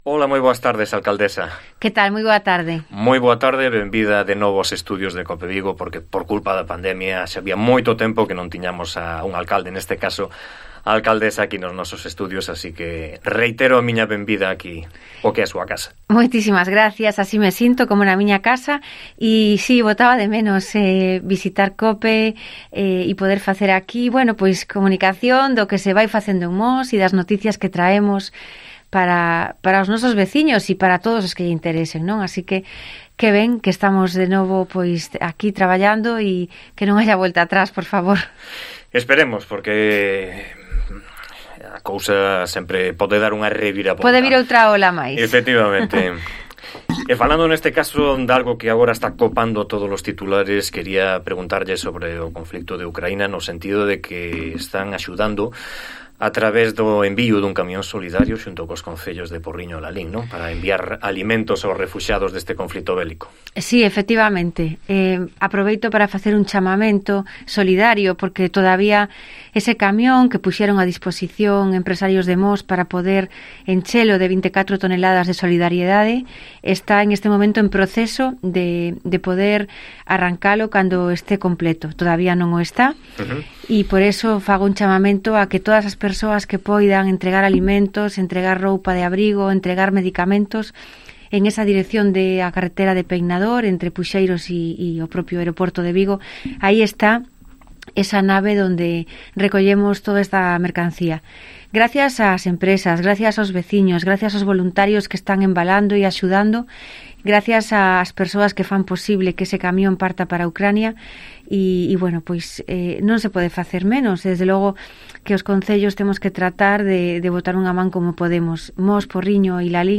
Entrevista con Nidia Arévalo, alcaldesa de Mos